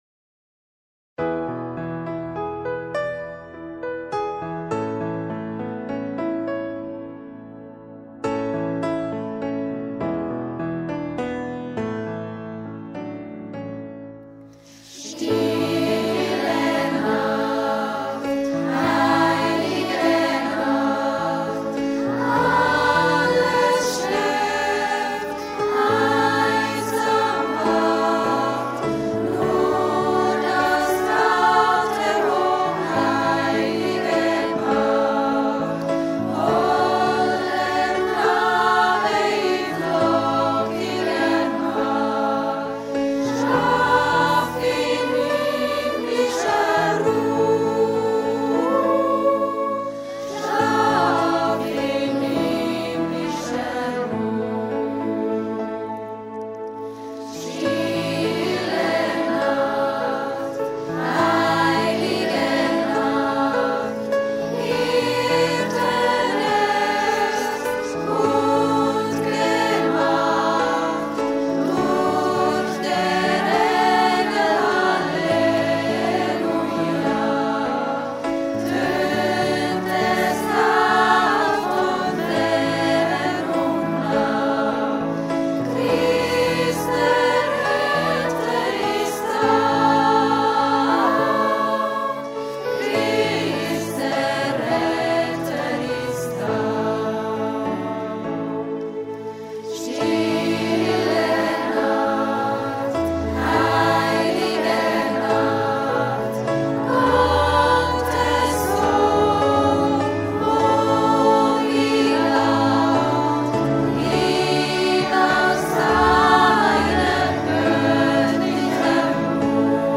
Krippenfeier 24. Dez. 2020
Die Lieder dazu wurdenalle vorgänig einzeln aufgenommen uns als Chor zusammen abgemischt.